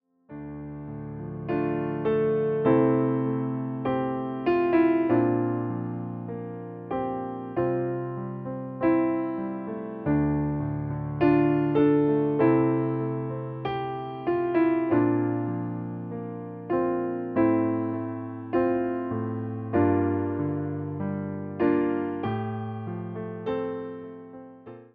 Wersja demonstracyjna:
97 BPM
F – dur